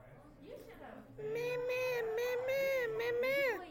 Mama mama sound effect
mama-mama-sound-effect.mp3